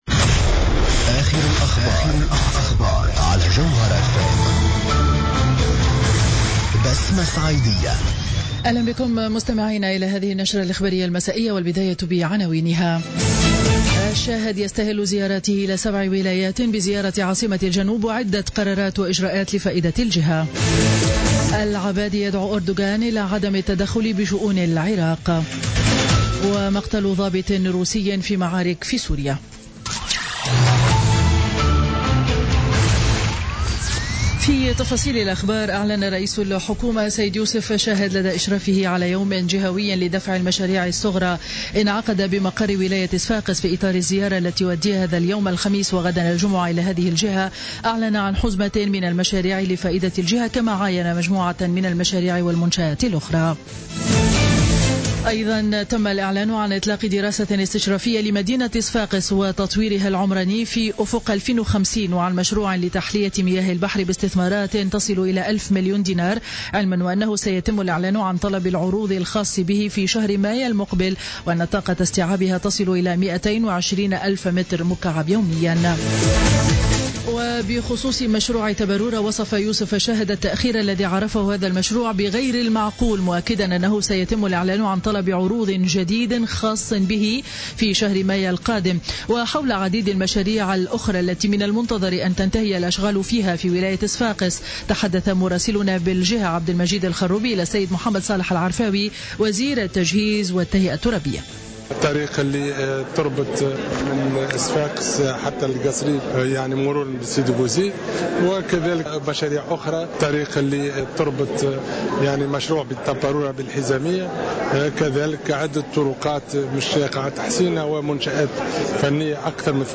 نشرة أخبار السابعة مساء ليوم الخميس 20 أفريل 2017